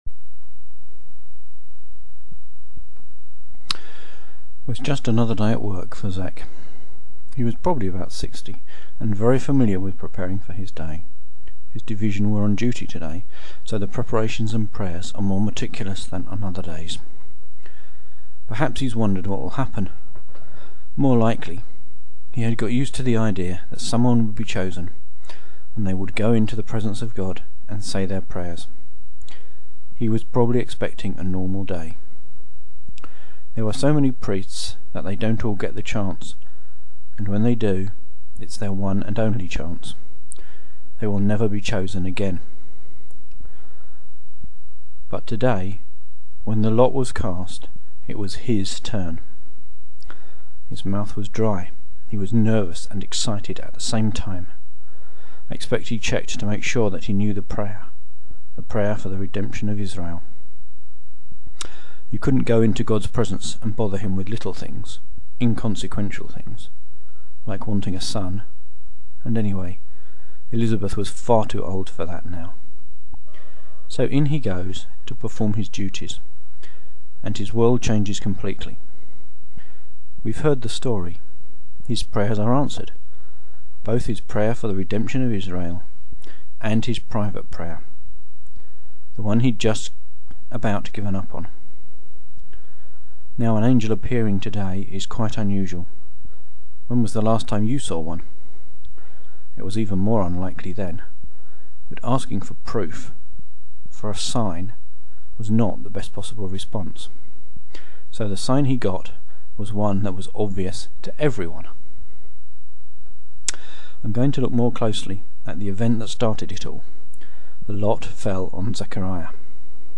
An old test recording of a sermon - to test blogger podcast capability